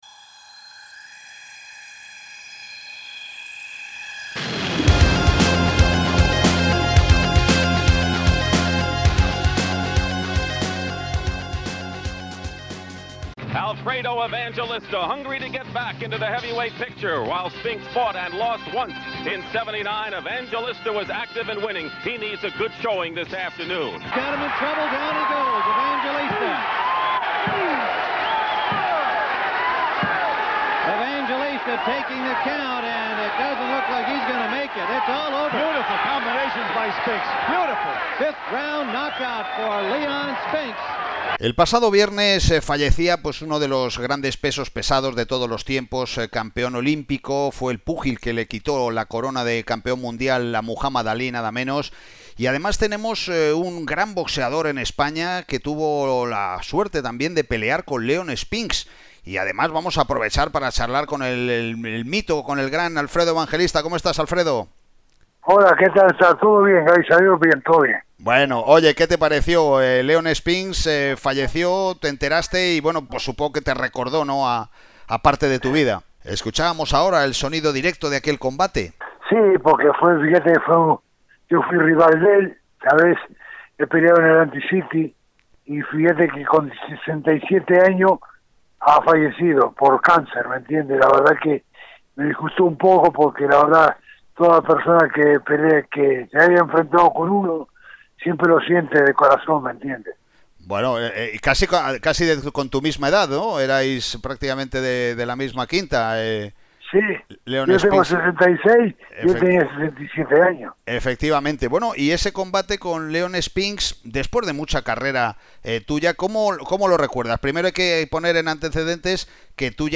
Esta es la primera parte de la entrevista.